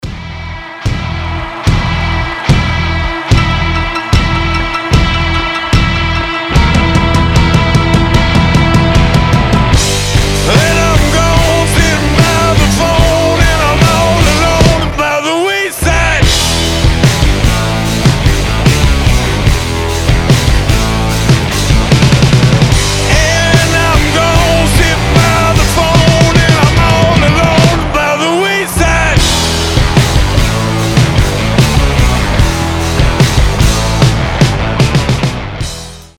• Качество: 320, Stereo
брутальные
саундтреки
электрогитара
Hard rock
heavy Metal